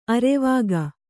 ♪ arevāga